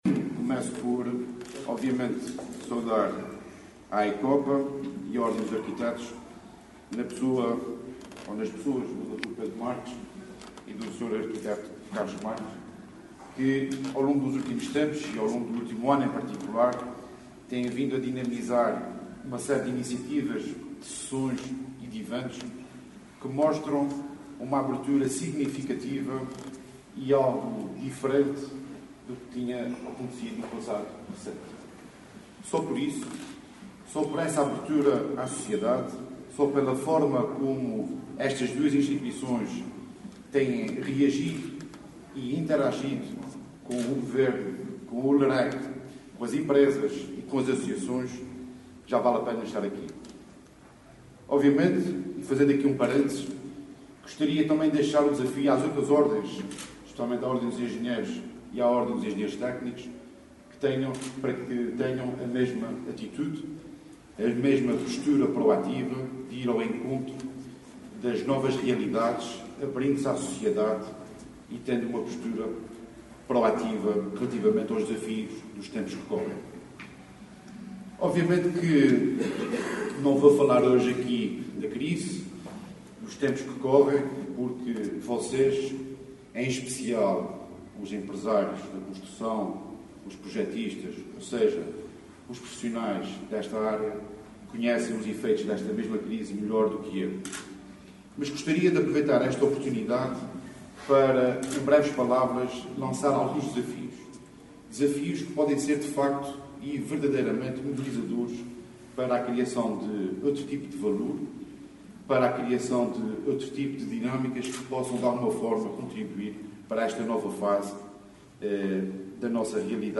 Para o Diretor Regional das Obras Públicas, Tecnologia e Comunicações, que falava na abertura da Feira de Promoção do Catálogo dos Materiais Endógenos produzidos ou transformados na Região Autónoma dos Açores, "não podemos continuar a olhar para os materiais da forma como olhávamos há 10 ou há 20 anos atrás, temos que ser criativos, temos que juntar à criatividade competências técnicas e, assim, inovar na produção de novas aplicações”.